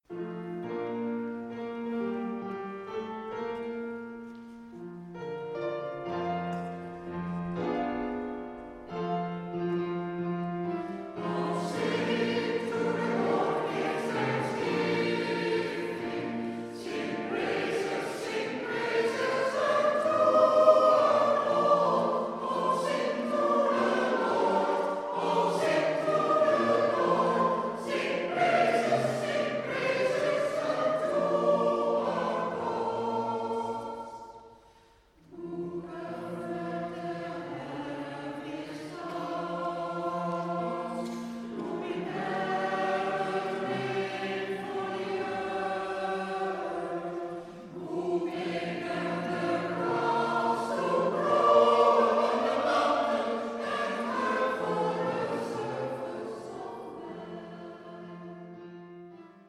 Het Interkerkelijk Koor Watergraafsmeer(IKW) werd opgericht op 7 januari 1995.
Het koor heeft in "De Meer" in Amsterdam een vaste plek verworven en veel leden zingen al vanaf de oprichting met veel plezier op de repetities, bij uitvoeringen en in kerkdiensten.